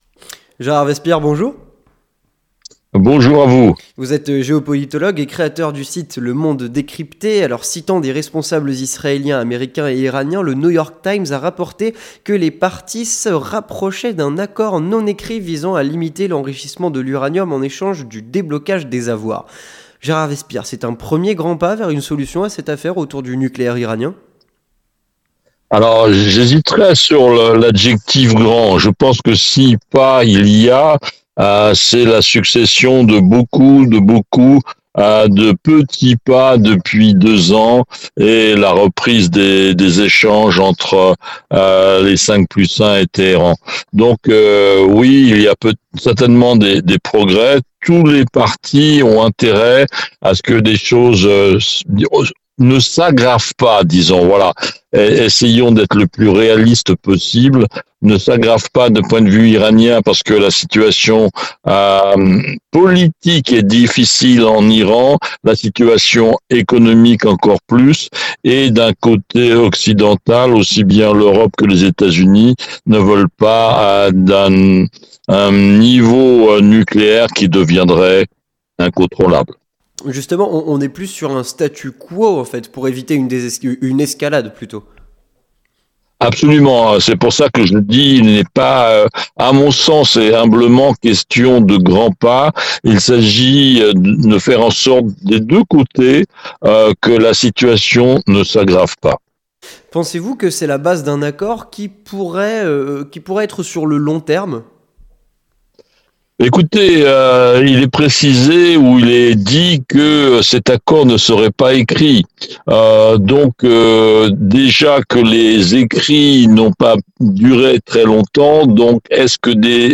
Entretien du 18h - Les négociations entre les USA et l'Iran